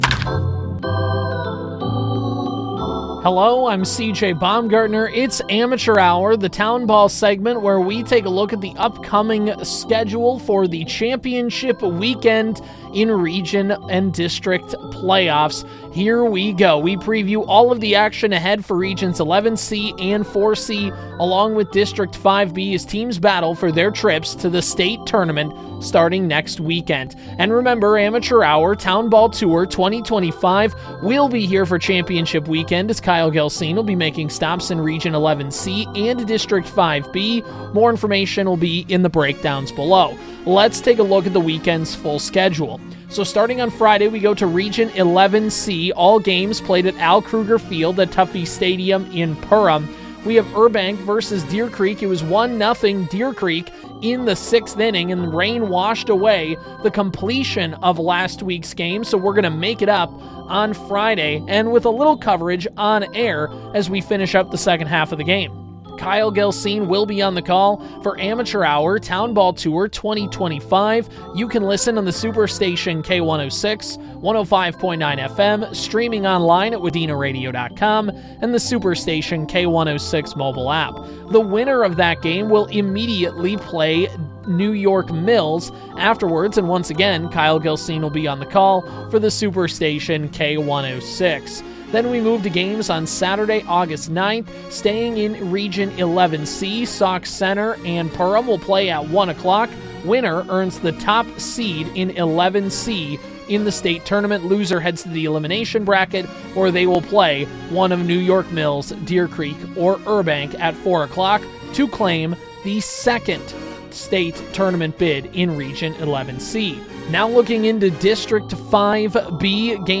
the bi-weekly town baseball segment covering all of the squads across central Minnesota